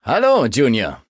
Datei:Maleadult01default convandale hello 0002ec9b.ogg
Fallout 3: Audiodialoge